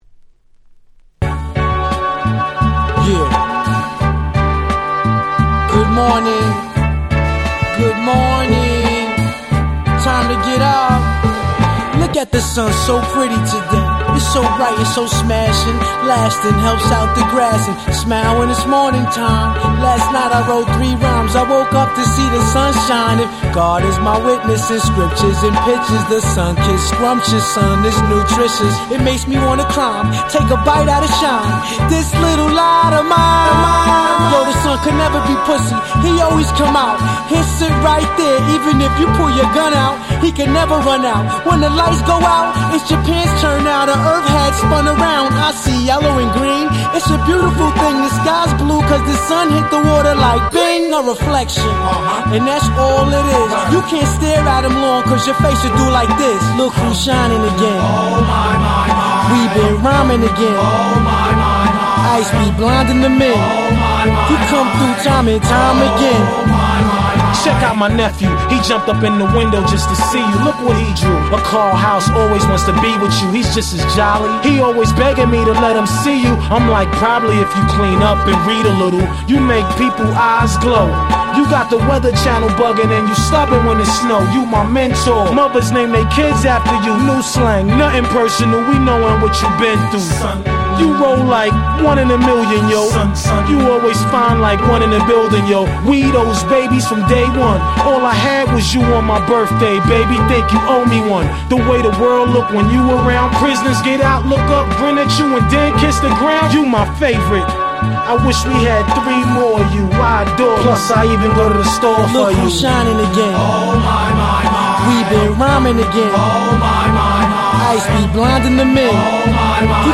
US Promo Only, Clean LP !!
01' Smash Hit Hip Hop Album !!